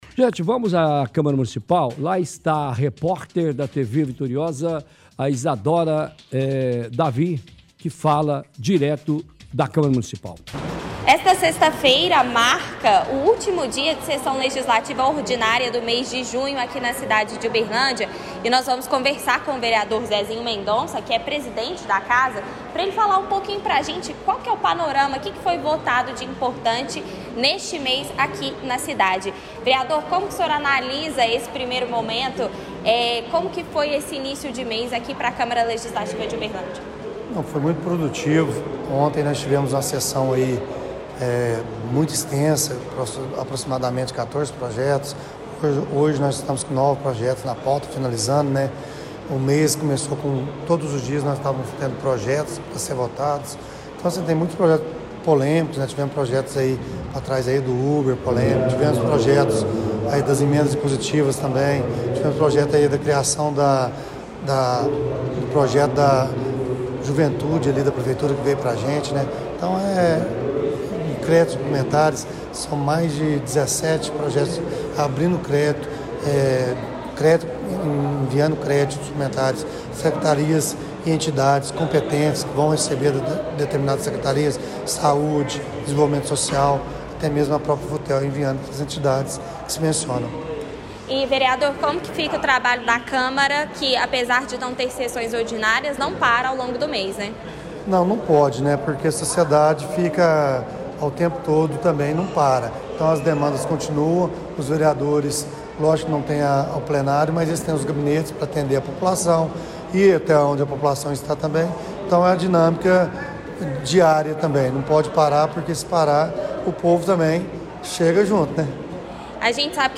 Entrevista com vereador Zezinho Mendonça
– Zezinho Mendonça fala ao vivo da câmara municipal.
entrevista-com-zezinho-mendonça.mp3